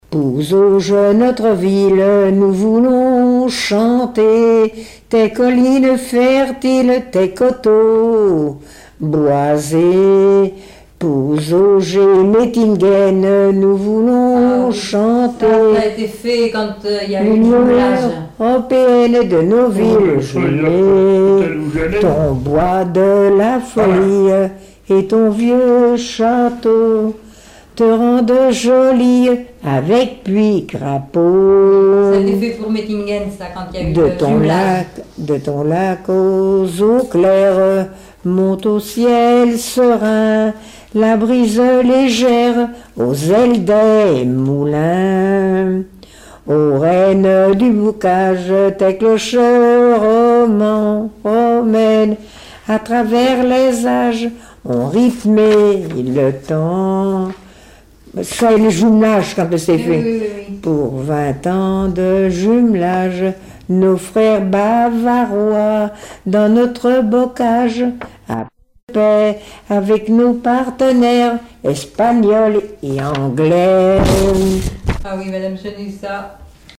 Chansons et témoignages
Pièce musicale inédite